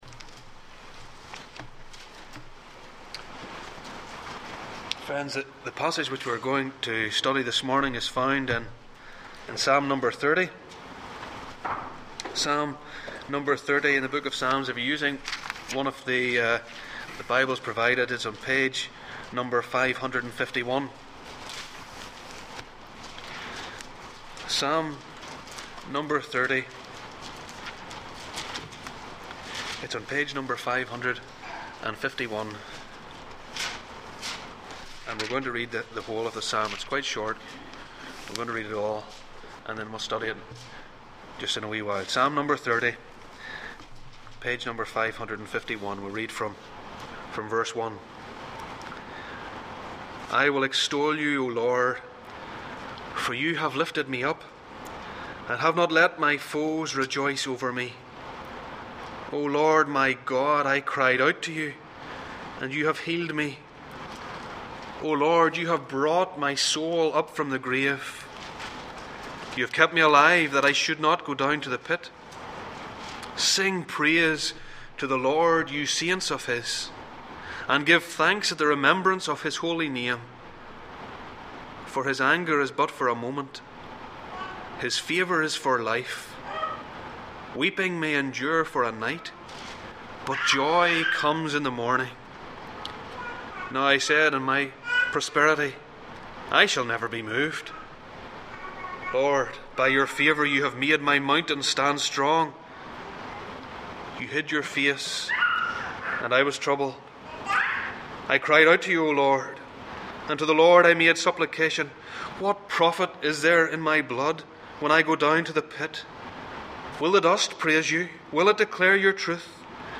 Psalm 30 Passage: Psalm 30:1-12, Hebrews 12:5-6 Service Type: Sunday Morning